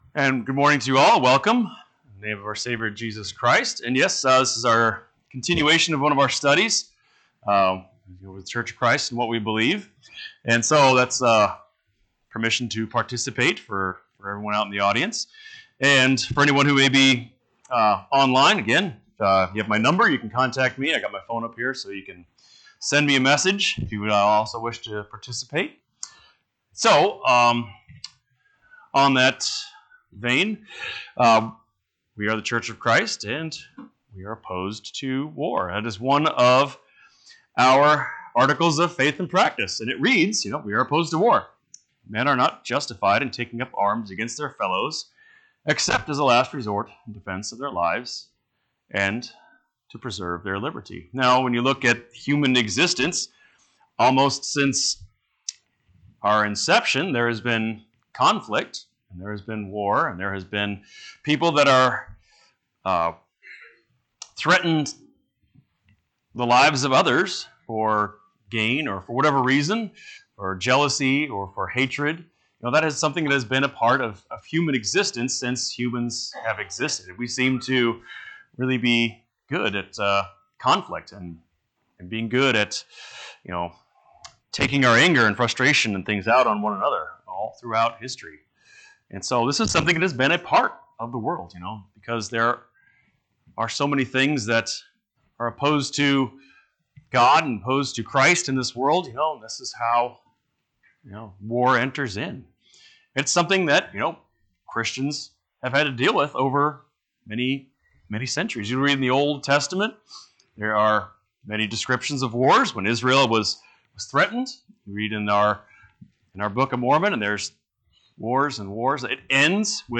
1/8/2023 Location: Phoenix Local Event